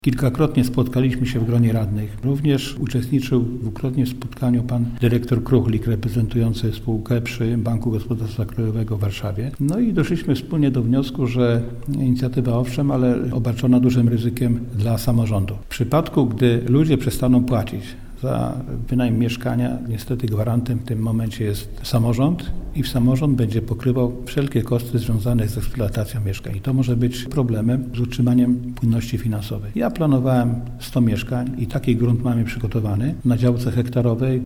– Byliśmy bardzo zainteresowani, ale po głębokiej analizie okazało się, że w programie Mieszkanie Plus jest pewien, niebezpieczny dla gmin haczyk – mówi Ignacy Odważny, burmistrz Sulechowa.